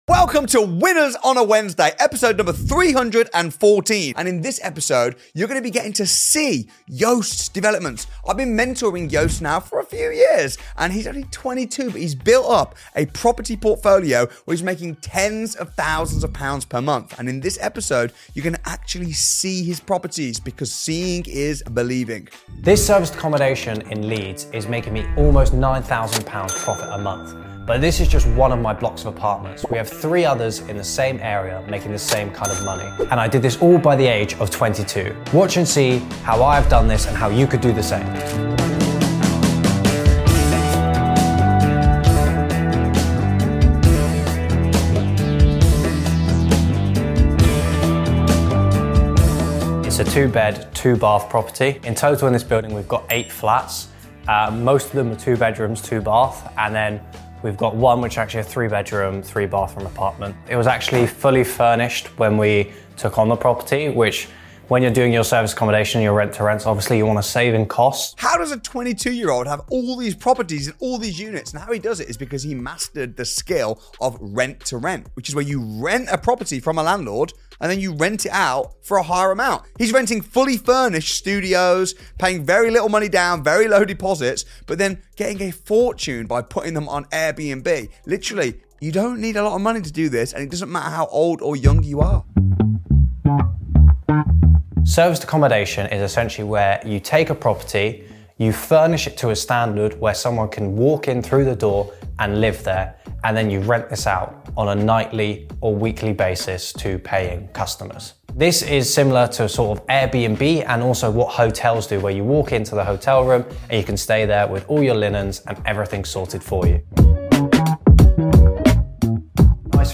If you're looking to get into property investment in the UK, especially as a non-UK national, this interview will blow your mind. We dive deep into the mindset, strategy, and hustle it takes to break into the industry and scale fast - even if you’re starting from scratch.